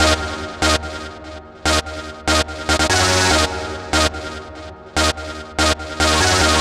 Stab 145-BPM D#.wav